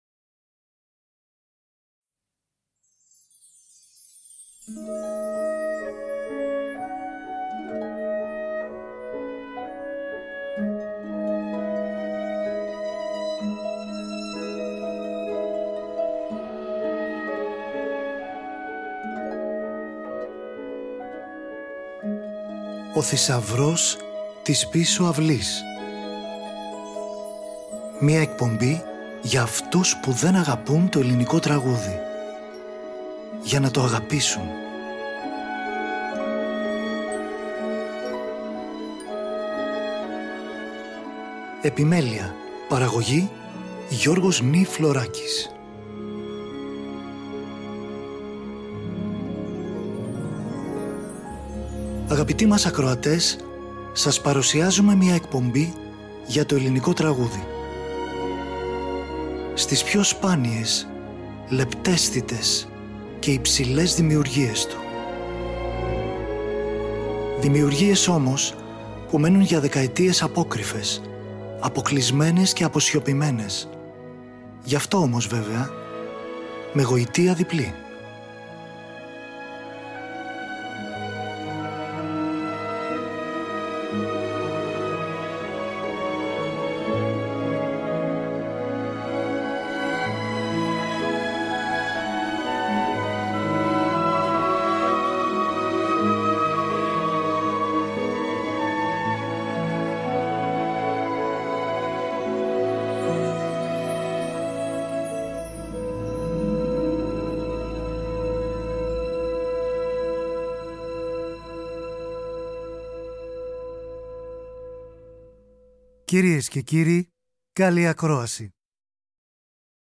Σύγχρονοι Συνθέτες
για πιάνο και τρομπέτα
για συμφωνική ορχήστρα
για φωνή και κουαρτέτο εγχόρδων
για ορχήστρα εγχόρδων και όμποε